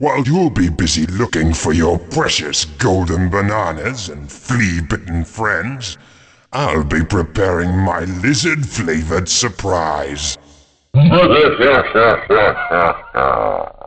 King K Rool to Donkey Kong about his surprise